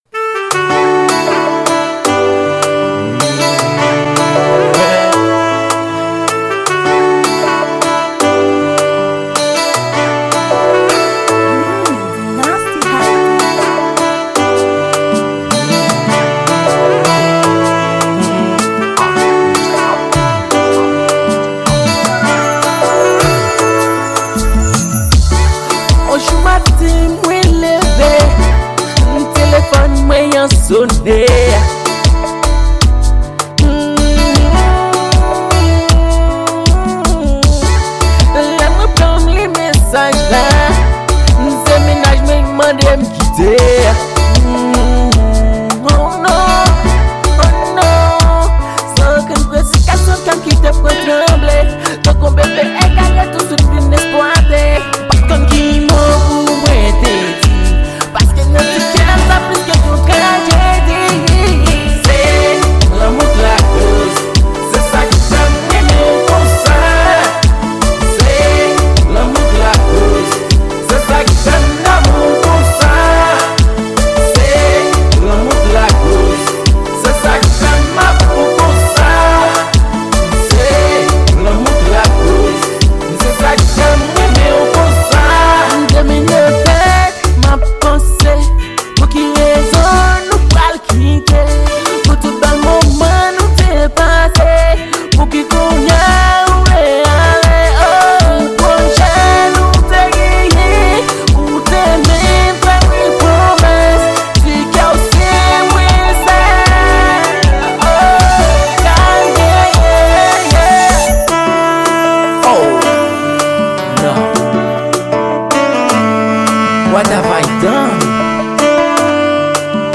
Genre: Zouk.